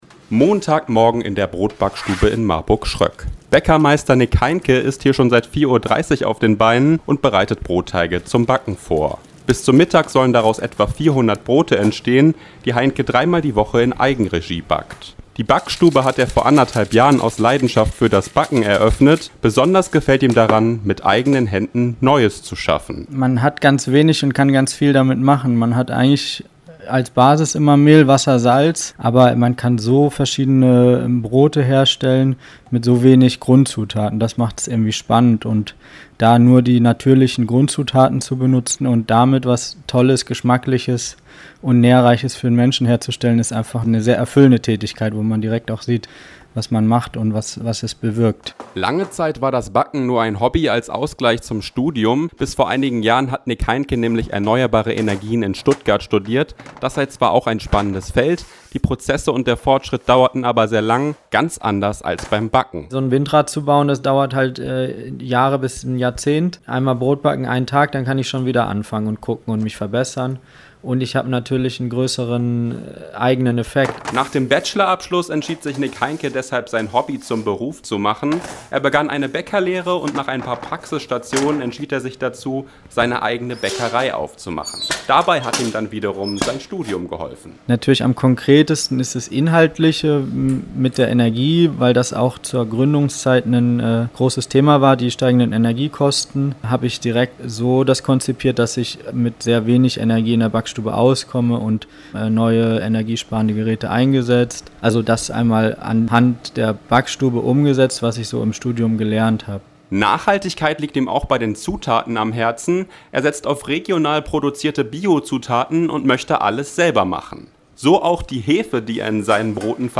hörst du im Feature: